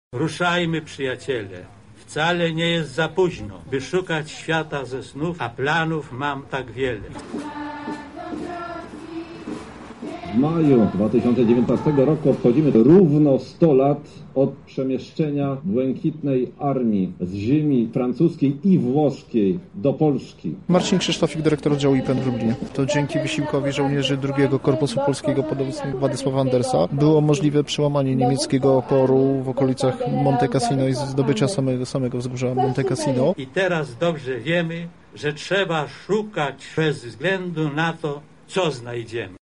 Na miejscu była nasza reporterka: